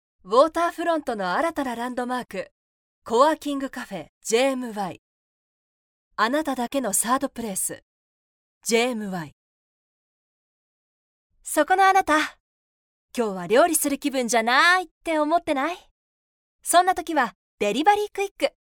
Japanese female voices